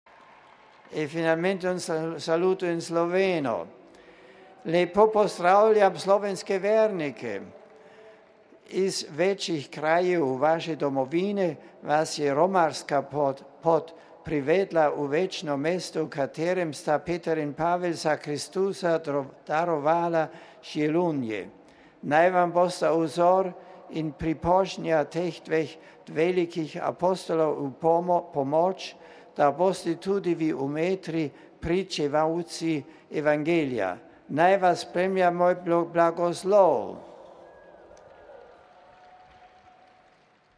Na današnji avdienci je bilo več skupin romarjev iz Slovenije.